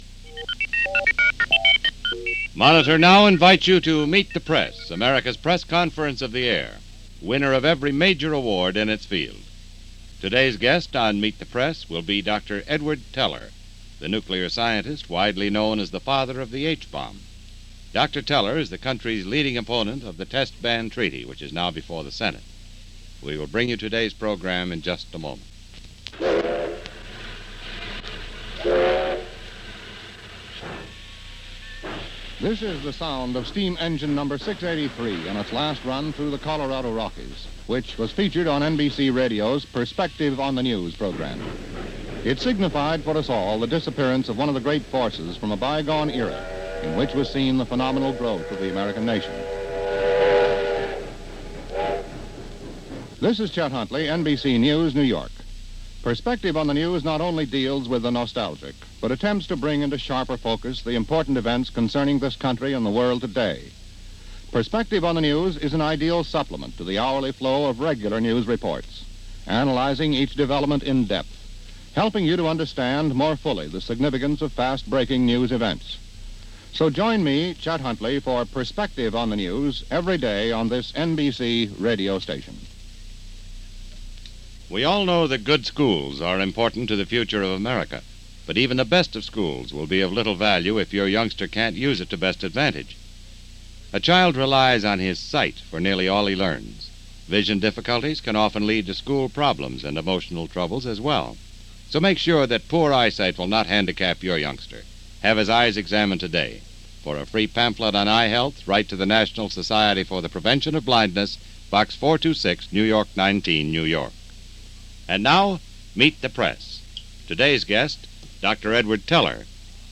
Dr. Edward Teller – Father of the H-Bomb, being interview during this episode of the NBC series Meet The Press on his resistance to the Nuclear de-armament Treaty, then in discussion between the U.S. and the Soviet Union.